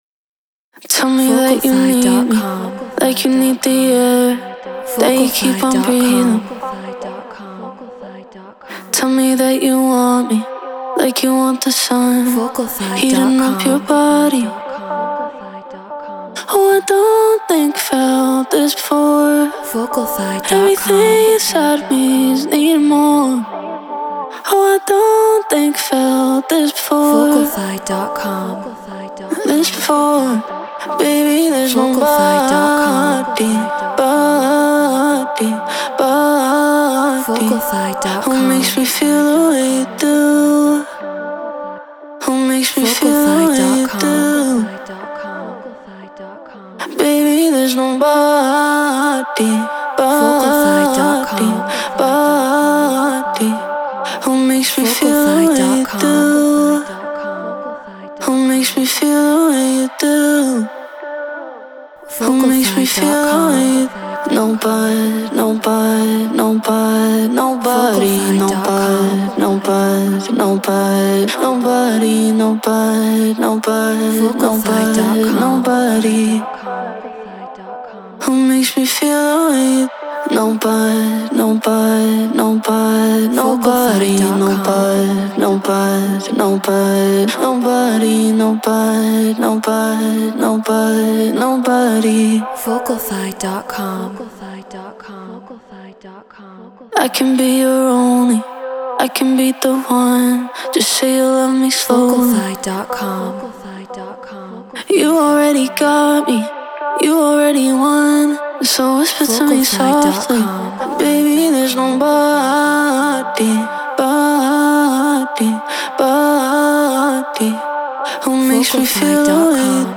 Afro House 120 BPM Amin
Shure SM7B Apollo Solo Logic Pro Treated Room